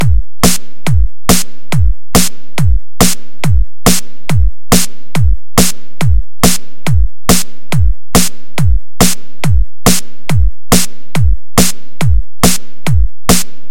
循环播放节拍和声音 " 160数字乙烯基循环播放
描述：Reverb Bass HipHop loooop .. 160bpm
Tag: 回路 啤酒花 乙烯基 嘻哈 低音